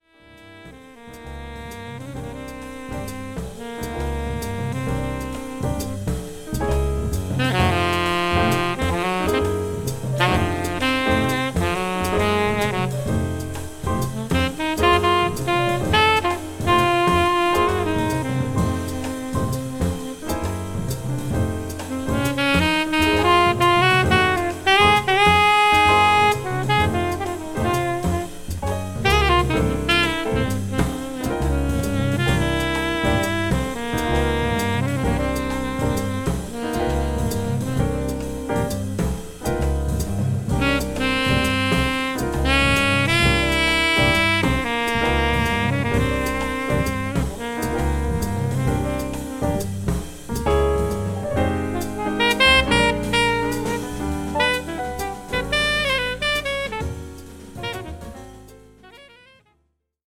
サックスもバックの演奏も滋味溢れる好演揃い、3曲あるオリジナル曲も充実した名作です。